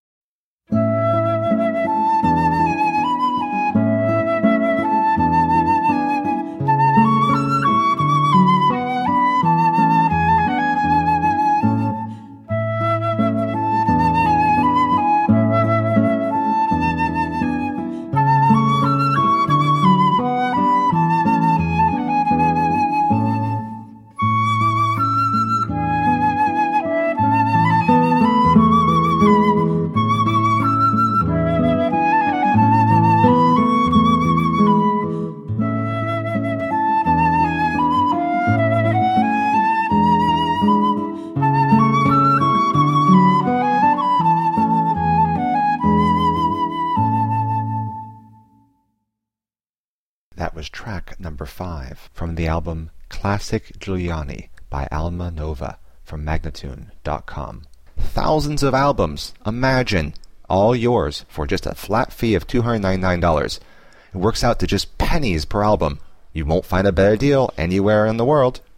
Lively flute/guitar duo.